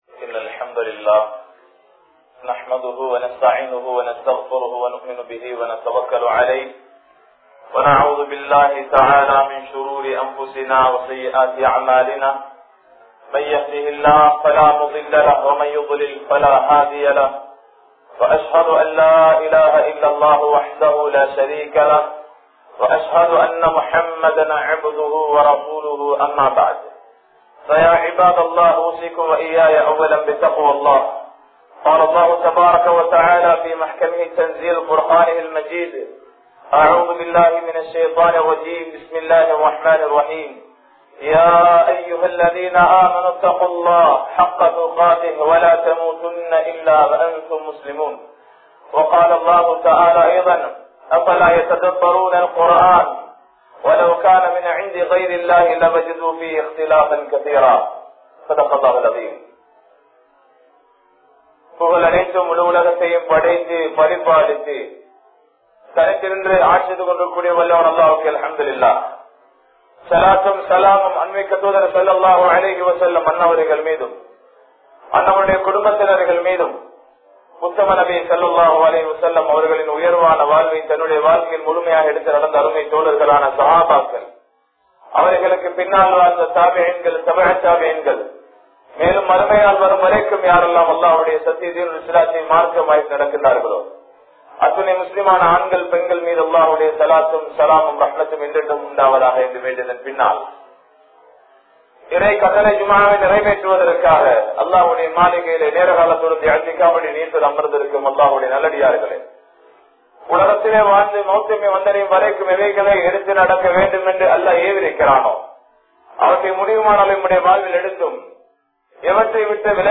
Inthak Qur′aanai Avarkal Sinthikka Vendaama?(இந்தக் குர்ஆனை அவர்கள் சிந்திக்க வேண்டாமா?) | Audio Bayans | All Ceylon Muslim Youth Community | Addalaichenai
Dambulla, Khairiya Jumua Masjidh